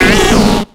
Cri de Pomdepik dans Pokémon X et Y.